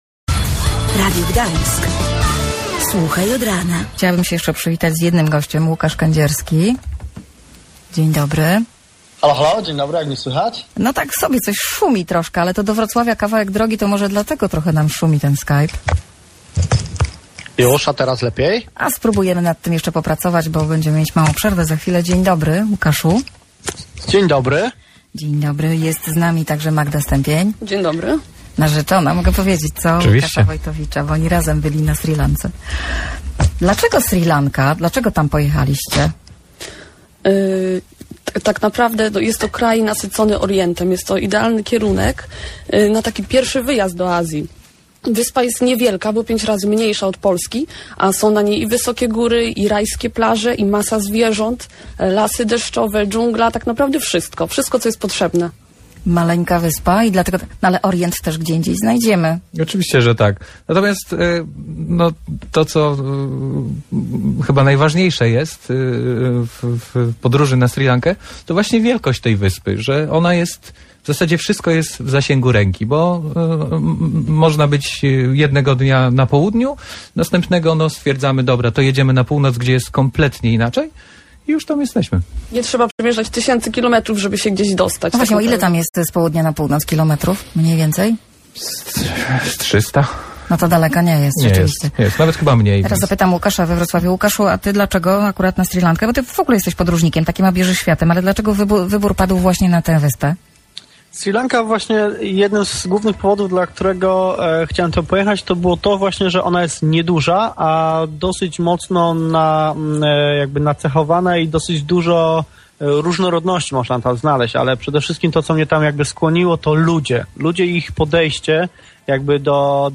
Audycja o Sri Lance w Radiu Gdańsk.
Jak zawsze audycja na żywo rządzi się swoimi prawami.